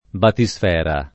[ bati S f $ ra ]